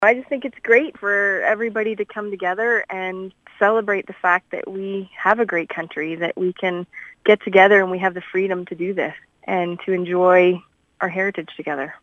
Councillor Tracy McGibbon says she always gets excited for Canada Day because of the community aspect of it.